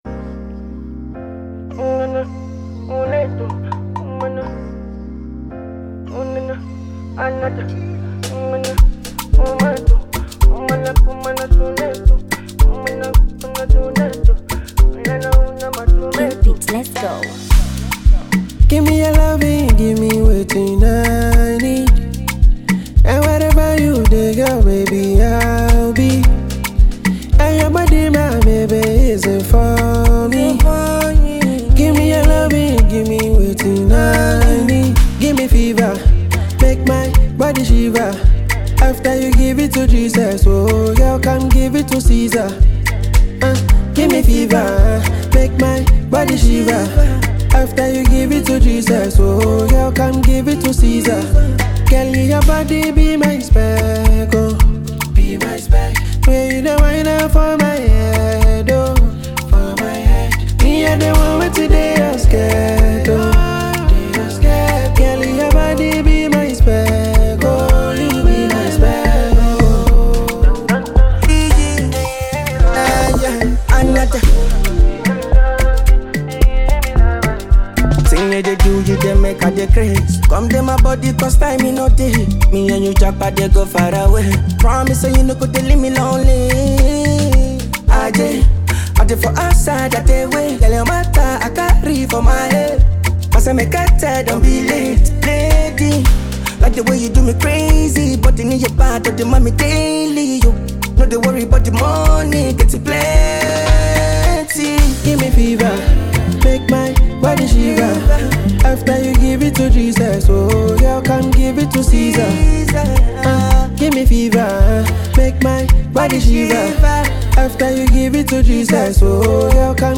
silky vocals
Ghana Afrobeat MP3